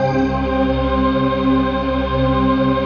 ATMOPAD22.wav